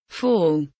fall kelimesinin anlamı, resimli anlatımı ve sesli okunuşu